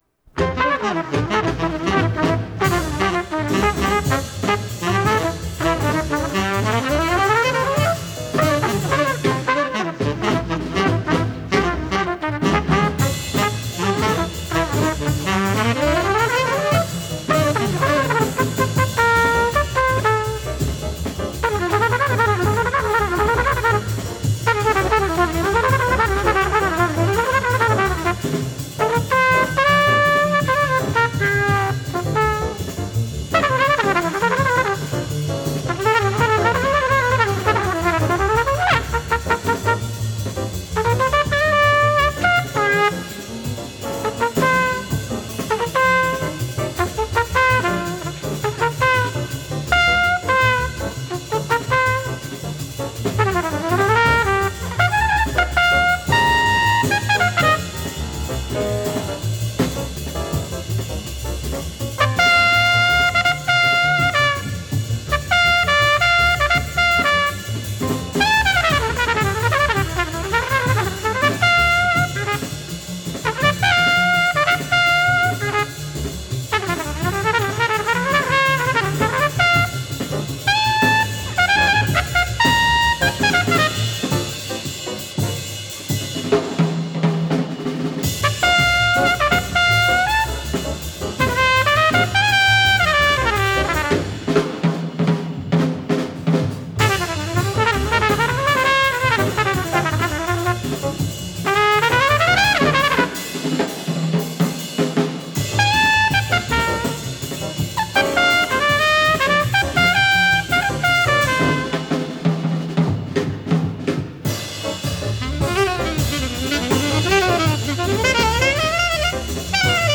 modern jazz
modal explorations